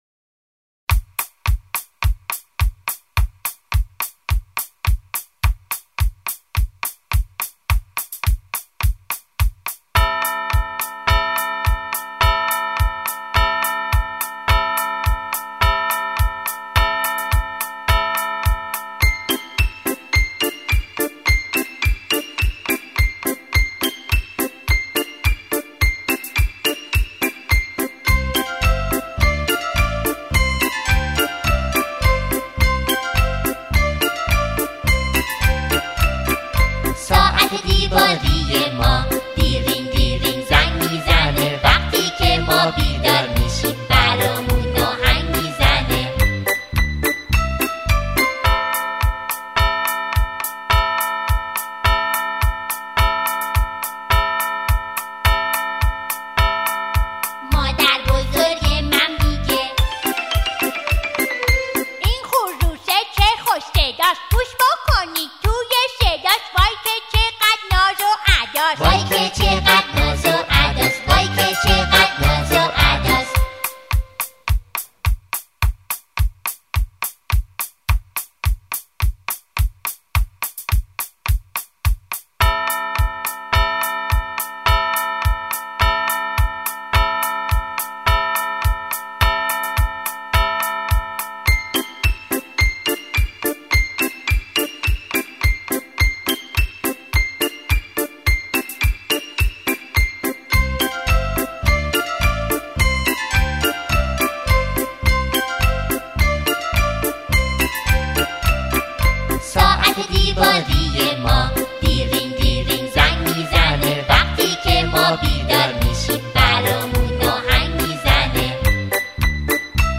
همراهی همخوان زن
در این قطعه، شعری کودکانه همخوانی می‌شود.